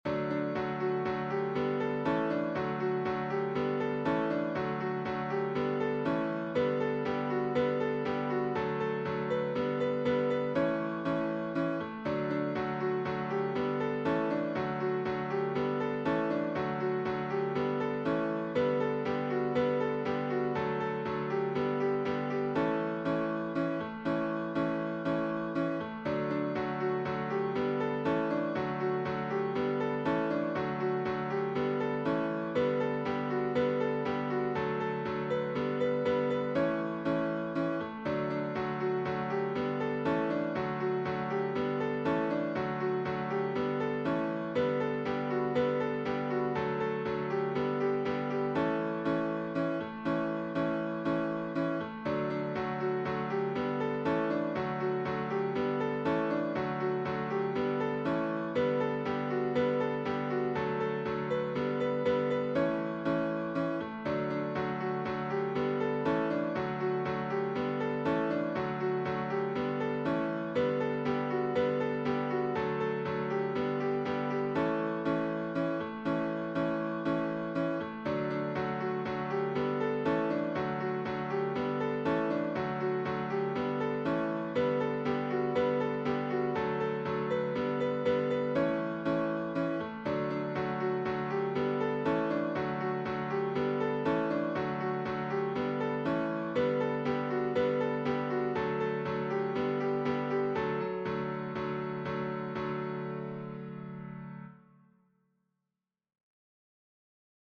(Recording) Unitarian Universalist hymn set to my own music